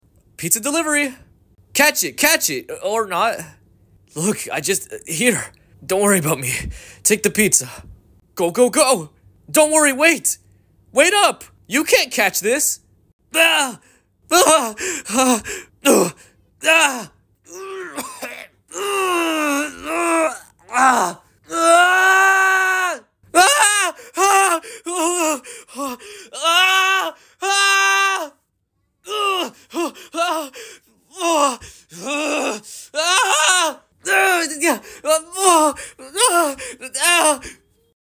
Short fanmade Elliot voicelines . sound effects free download